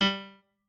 pianoadrib1_35.ogg